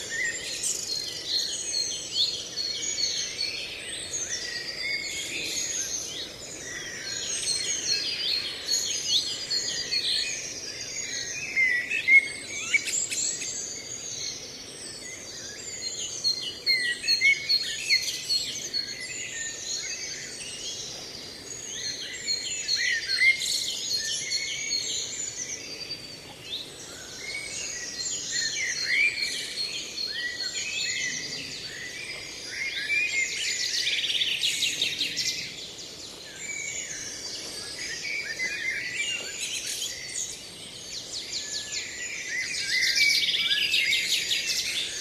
birds_screaming_loop.ogg